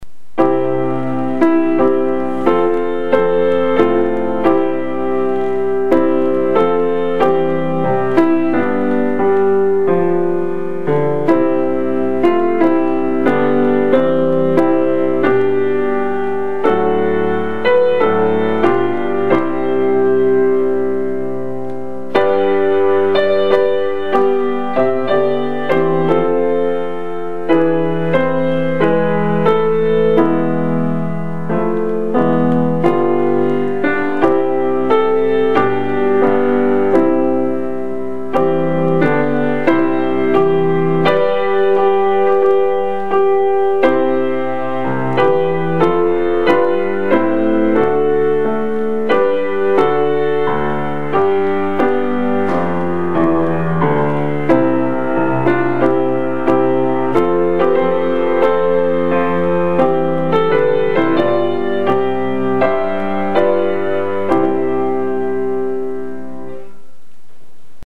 ピアノ演奏
piano001.mp3